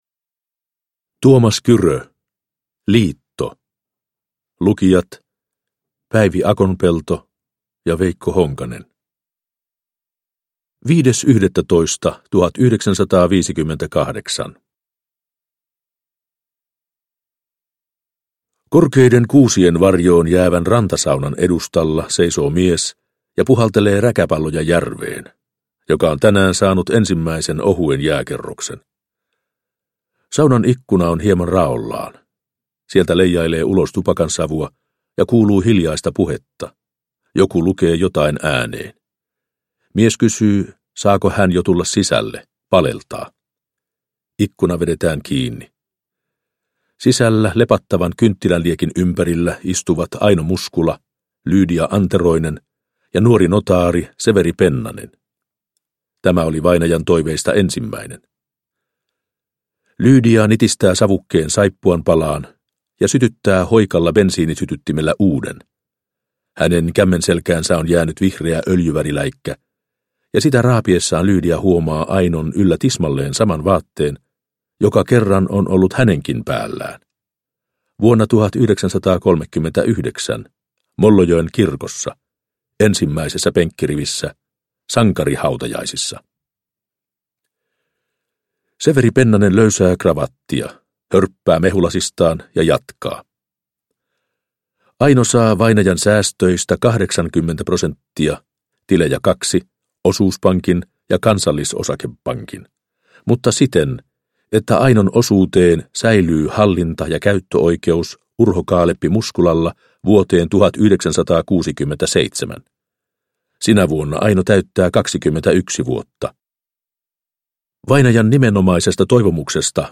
Liitto – Ljudbok – Laddas ner